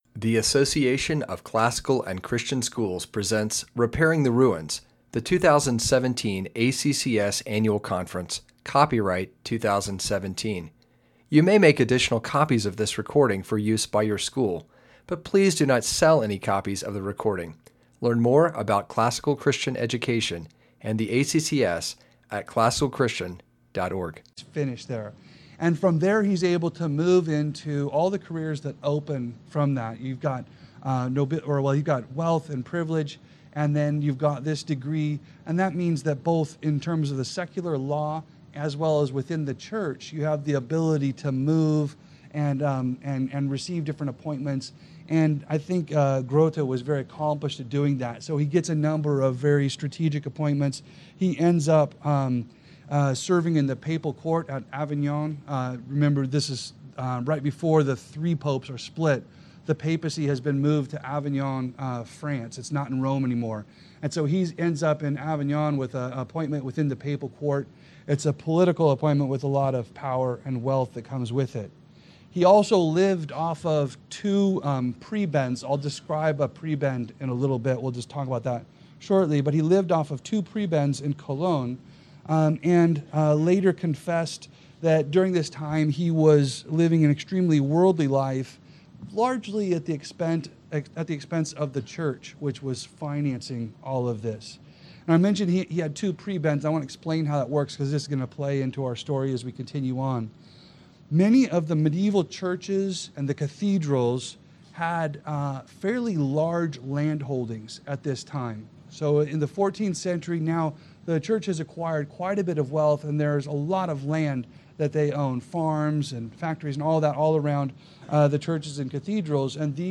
2017 Workshop Talk | 0:50:09 | All Grade Levels, History
The Life of Alfred Additional Materials The Association of Classical & Christian Schools presents Repairing the Ruins, the ACCS annual conference, copyright ACCS.